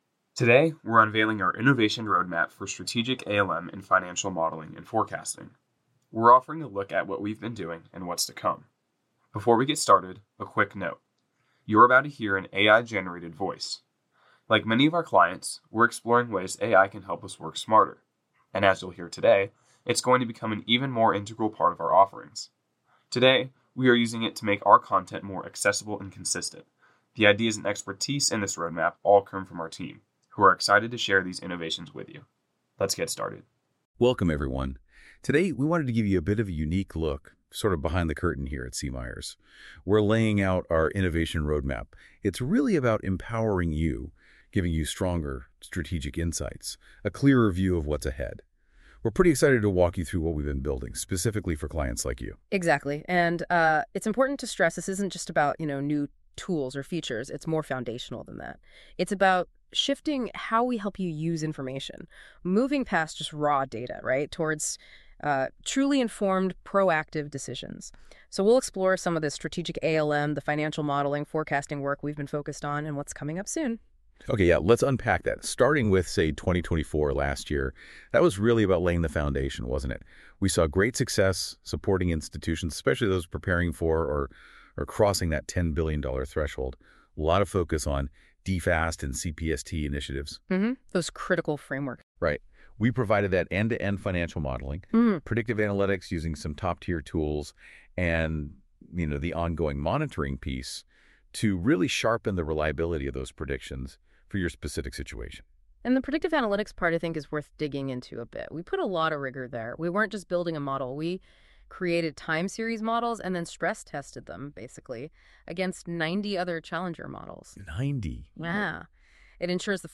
Below is an AI generated audio recording of our continuous innovations.